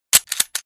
gg_reload_beta.wav